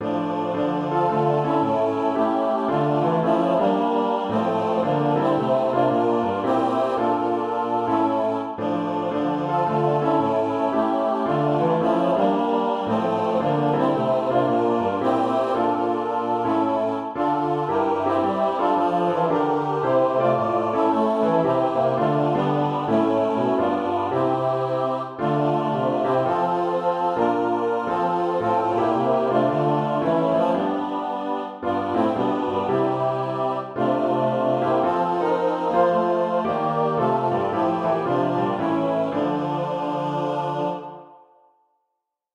It is a swing style take on the Christmas carol God Rest Ye, Merry Gentlemen.